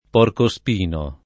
porcospino [ p q rko S p & no ]